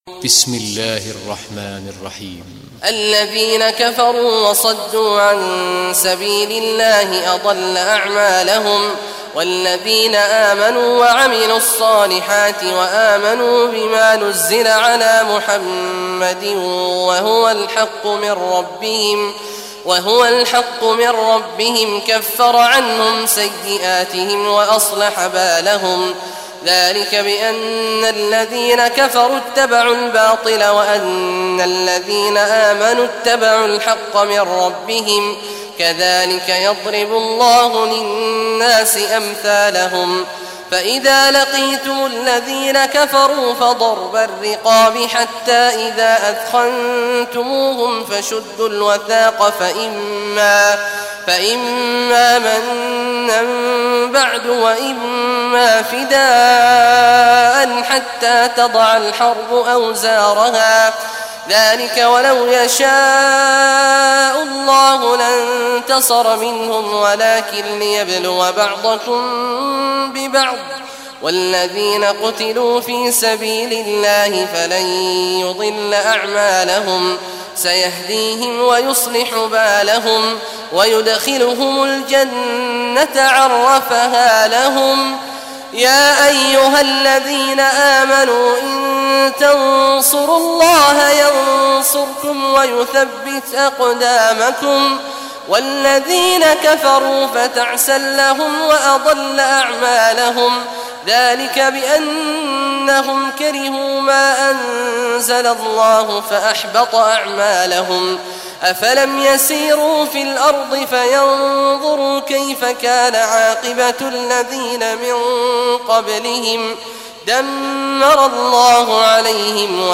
Surah Muhammad Recitation by Sheikh Awad al Juhany
Surah Muhammad, listen or play online mp3 tilawat / recitation in Arabic in the beautiful voice of Sheikh Awad al Juhany.